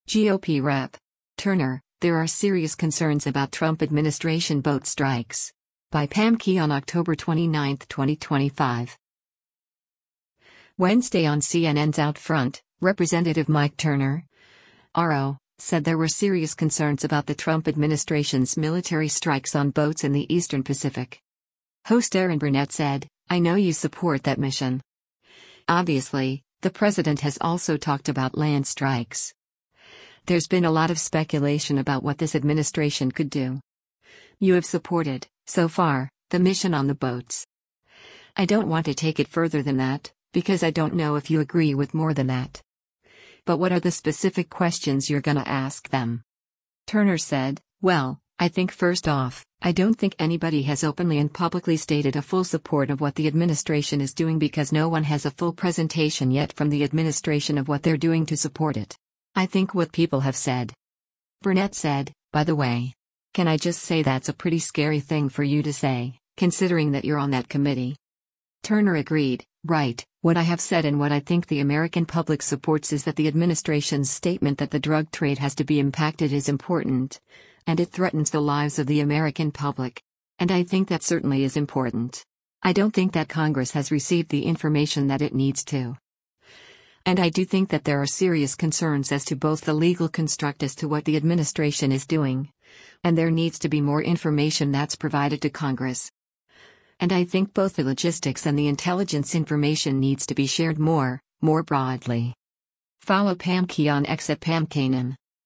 Wednesday on CNN’s “OutFront,” Rep. Mike Turner (R-OH) said there were “serious concerns” about the Trump administration’s military strikes on boats in the eastern Pacific.